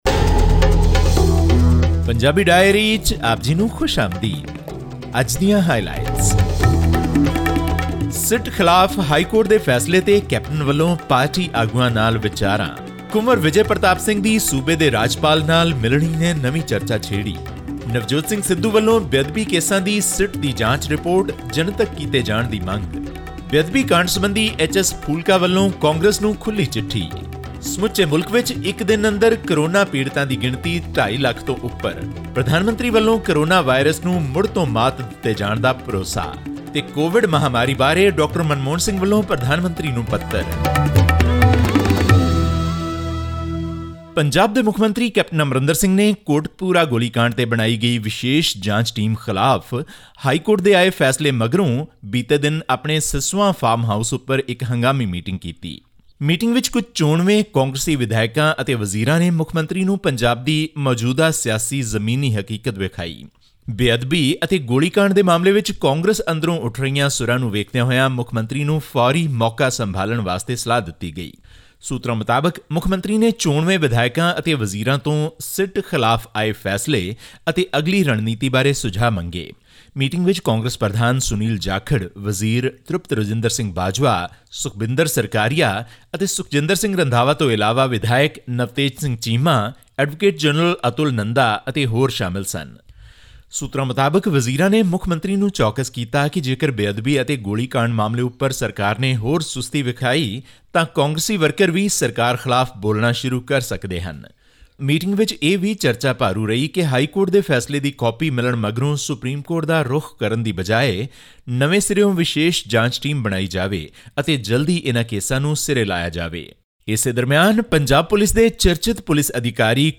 Former Prime Minister Manmohan Singh has penned a letter to PM Narendra Modi offering five suggestions to contain the escalating coronavirus cases in India. This and much more in our weekly news segment from Punjab.